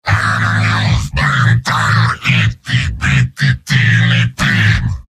Giant Robot lines from MvM. This is an audio clip from the game Team Fortress 2 .
Heavy_mvm_m_taunts12.mp3